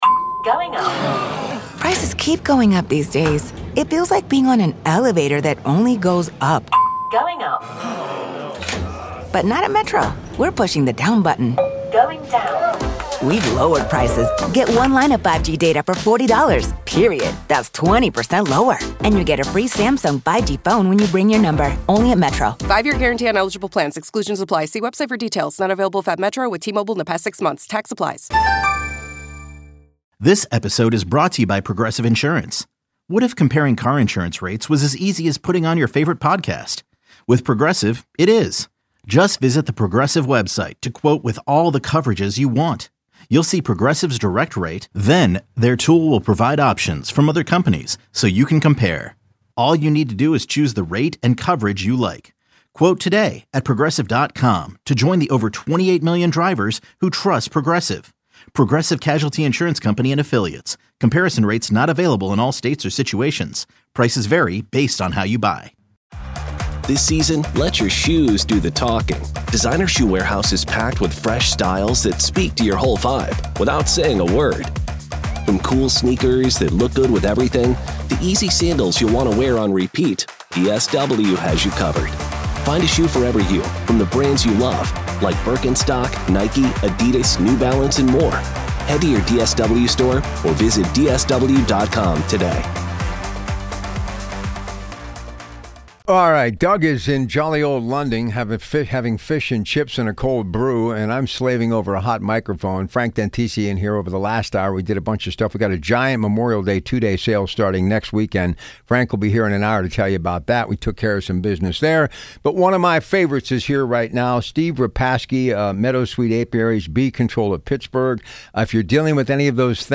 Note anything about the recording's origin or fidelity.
The Organic Gardener airs live Sundays at 7:00 AM on KDKA Radio.